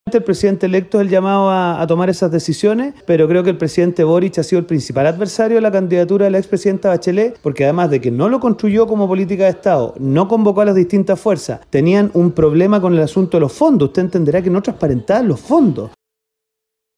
Desde la oposición, el diputado de Renovación Nacional, Diego Schalper, sostuvo que el presidente Boric ha sido el principal opositor a la candidatura de Bachelet.